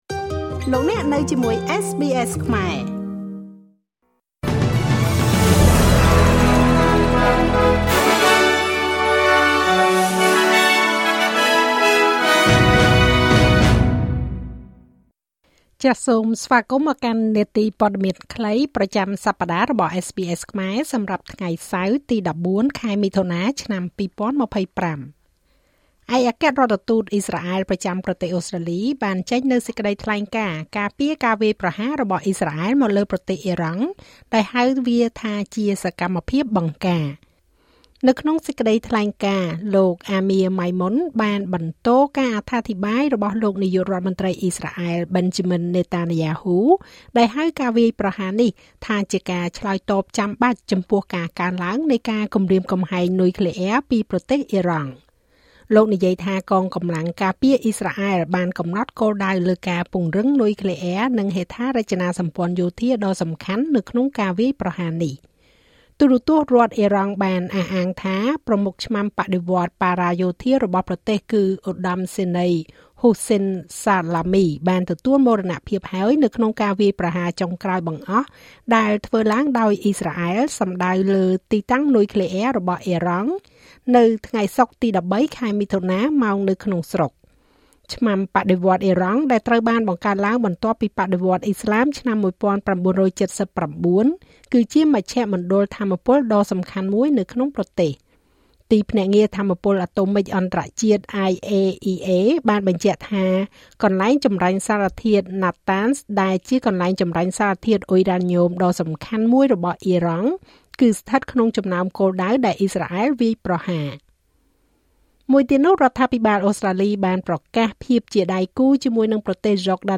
នាទីព័ត៌មានខ្លីប្រចាំសប្តាហ៍របស់SBSខ្មែរ សម្រាប់ថ្ងៃសៅរ៍ ទី១៤ ខែមិថុនា ឆ្នាំ២០២៥